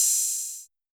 UHH_ElectroHatC_Hit-29.wav